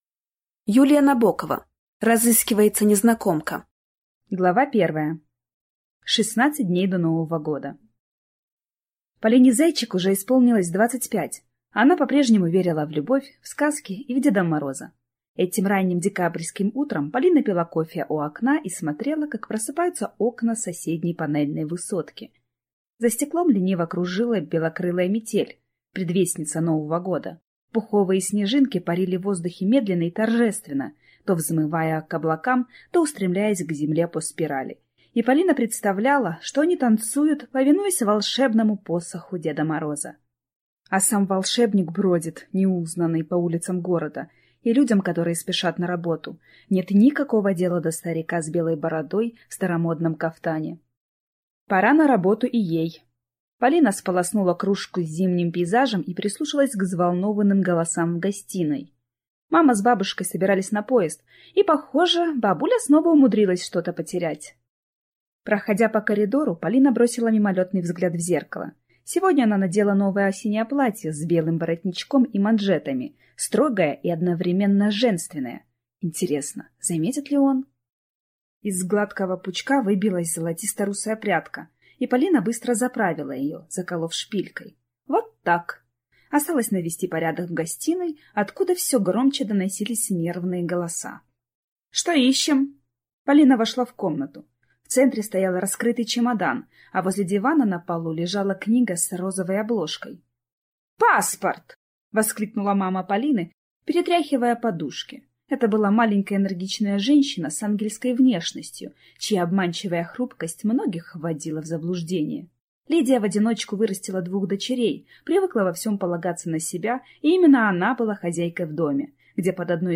Аудиокнига Разыскивается незнакомка | Библиотека аудиокниг